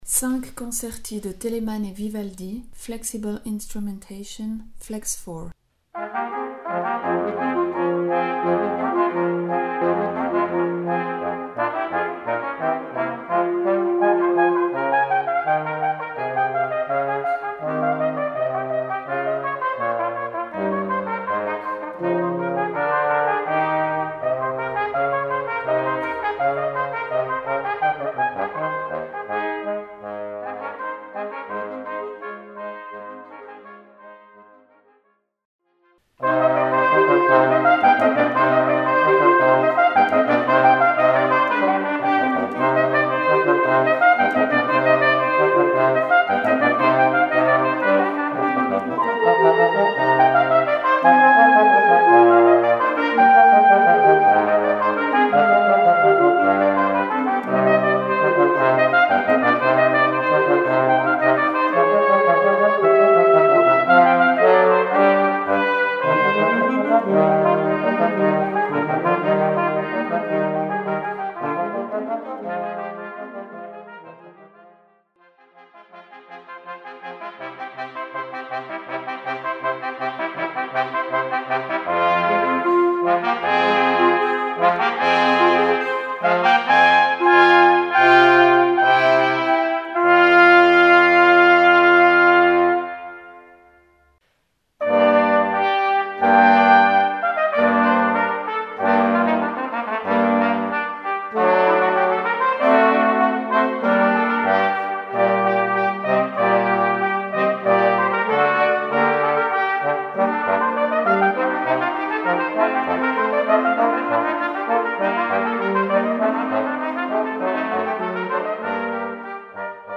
Ensemble 4 voix Flex
Classic Up to Date / Musique classique